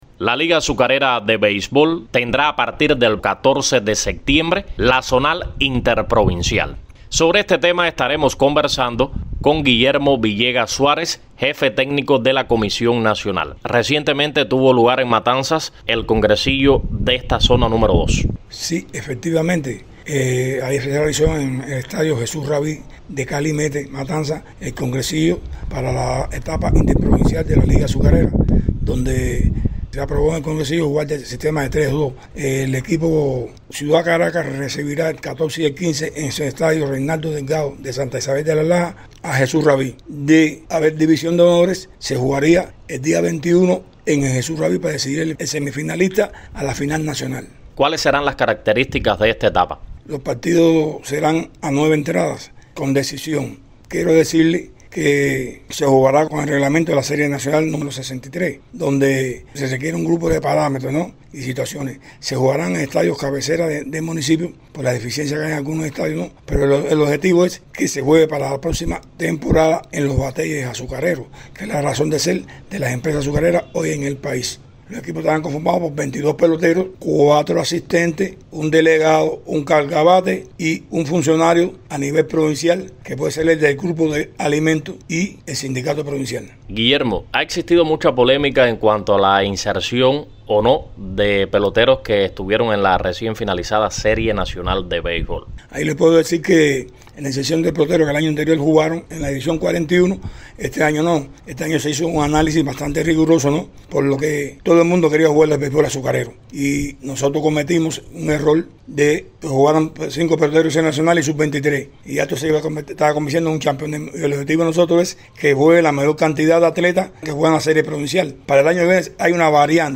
Este 14 de septiembre dará inicio en el estadio Reinaldo Delgado, de Santa Isabel de las Lajas, el primer partido de la semifinal interprovincial del béisbol azucarero entre el local, la Empresa Agroindustrial Azucarera Ciudad Caracas y el Jesús Rabí, de Calimete en Matanzas. A propósito del certamen versa la siguiente entrevista